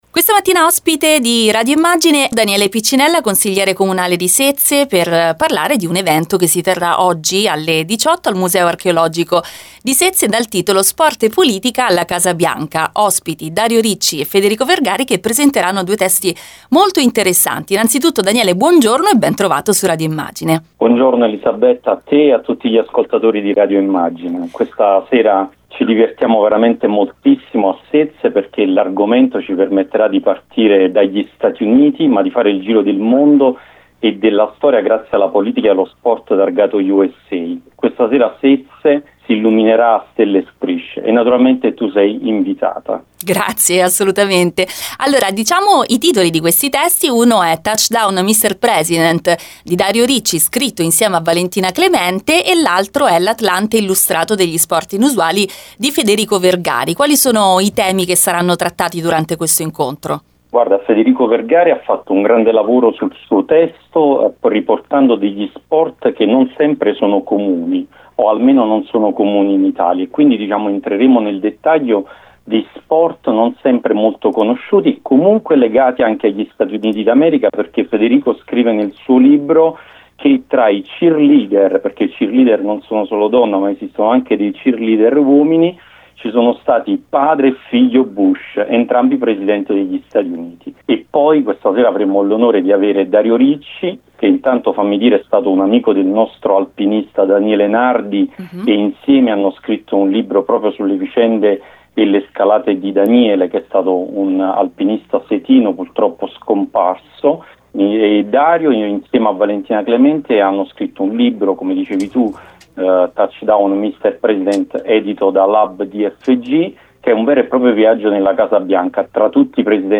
Il consigliere comunale di Sezze Daniele Piccinella, ai microfoni di Radio Immagine, ha spiegato che l’evento ha lo scopo di parlare e di sport e politica, del loro rapporto e soprattutto di come gli americani vivono questa simbiosi. Ascolta l’intervista: